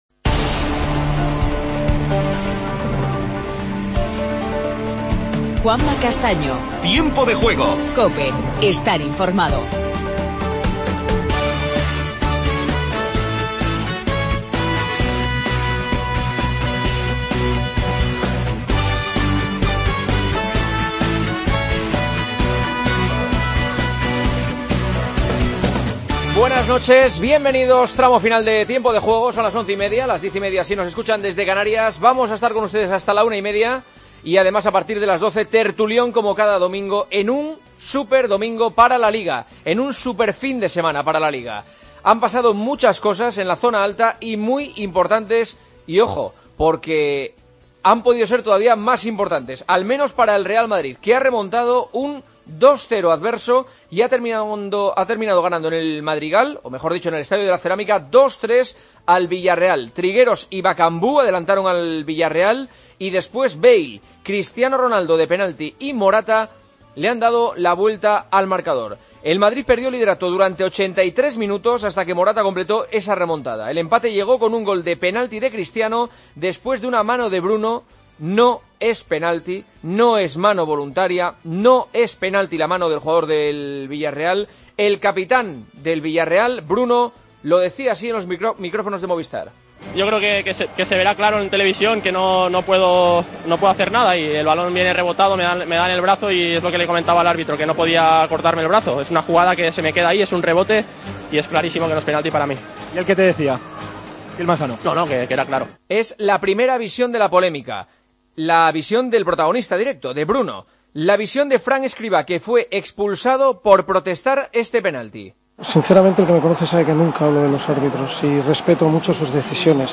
Entrevista a Koke y escuchamos a Sergio Ramos.